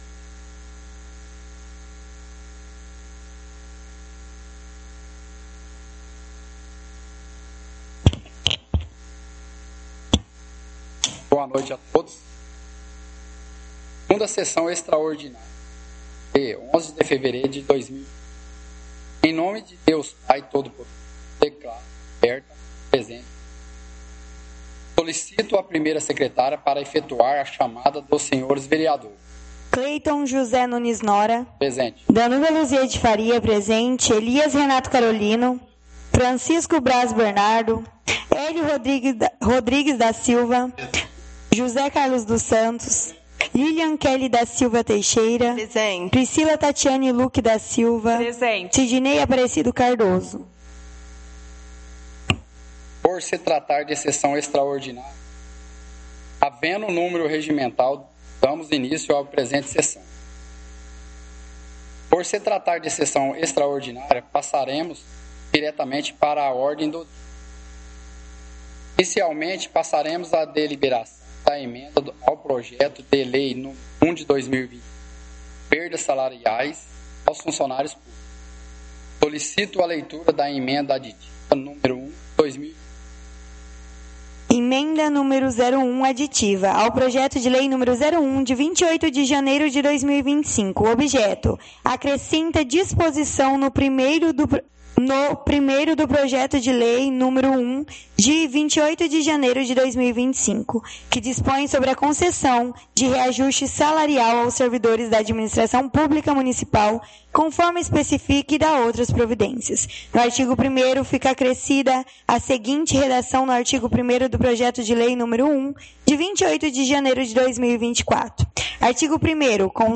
Áudio da 2ª Sessão Extraordinária – 11/02/2025